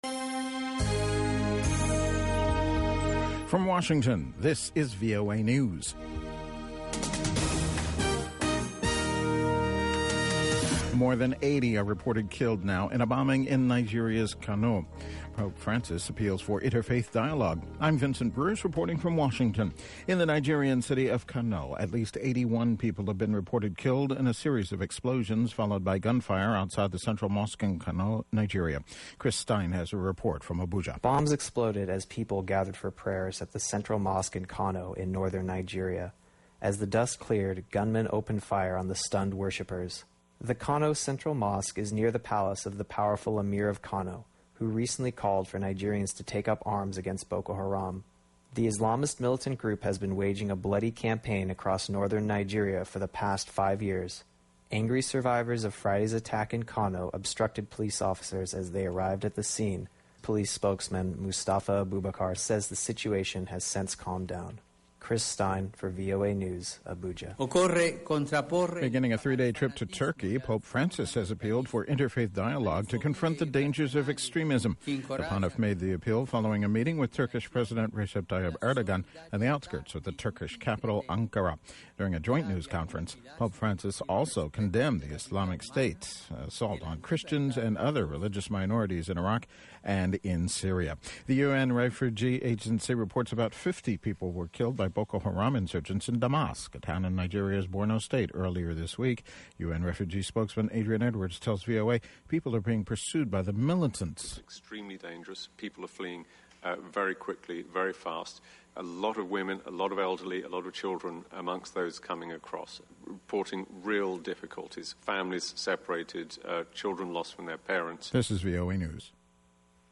the best mix of pan-African music